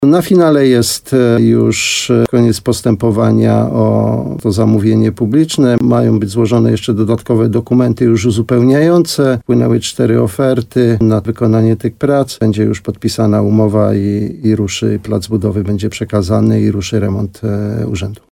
Jak zaznacza Janusz Opyd, wójt gminy Szczawa, która powstała 1 stycznia 2025 roku, wszystko jest już przygotowane do rozpoczęcia prac.